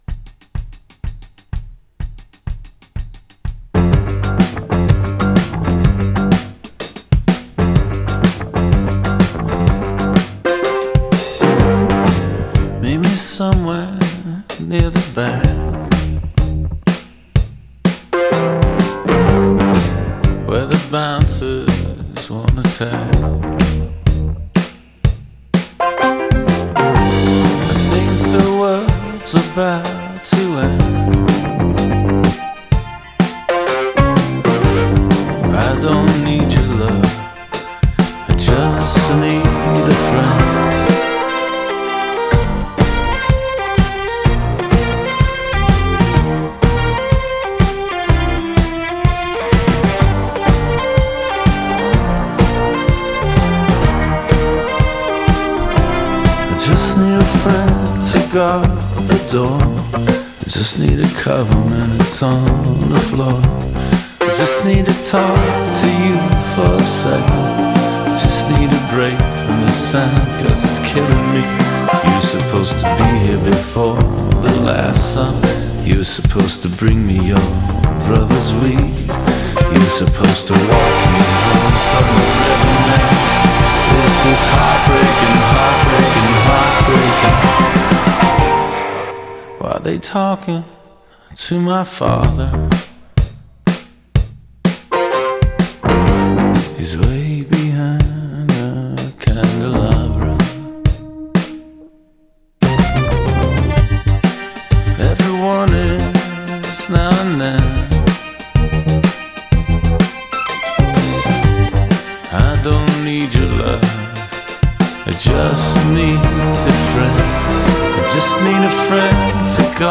This sparse but funky side project collaboration